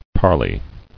[par·ley]